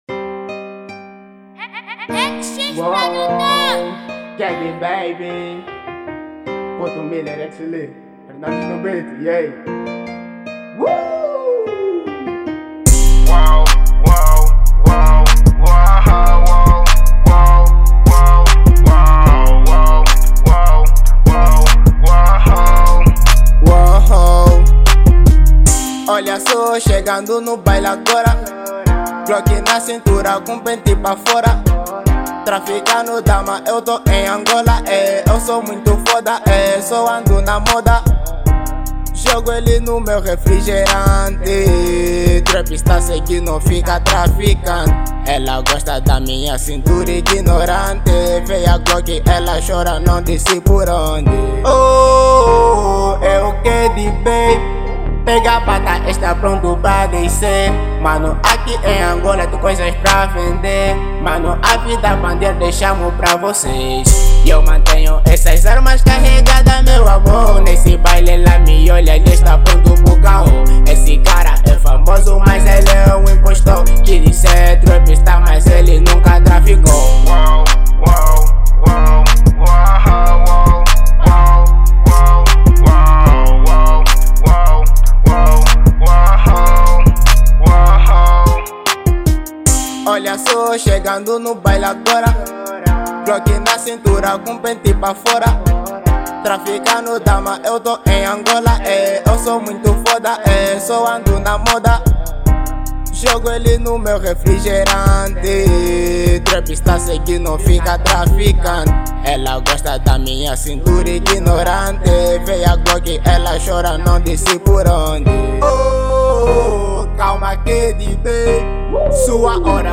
Trap